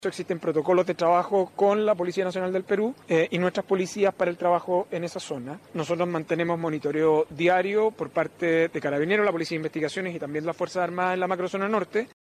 El ministro de Seguridad Pública, Luis Cordero, explicó que este comportamiento responde, en parte, a los protocolos conjuntos que existen entre Carabineros, la PDI y la Policía Nacional del Perú.